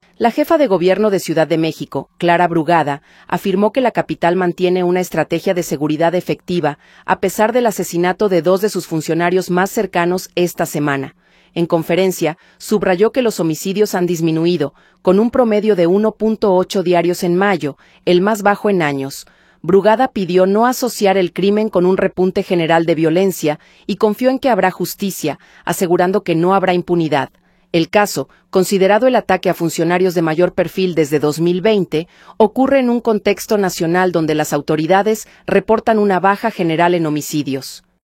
La jefa de Gobierno de Ciudad de México, Clara Brugada, afirmó que la capital mantiene una estrategia de seguridad efectiva, a pesar del asesinato de dos de sus funcionarios más cercanos esta semana. En conferencia, subrayó que los homicidios han disminuido, con un promedio de 1.8 diarios en mayo, el más bajo en años. Brugada pidió no asociar el crimen con un repunte general de violencia y confió en que habrá justicia, asegurando que no habrá impunidad.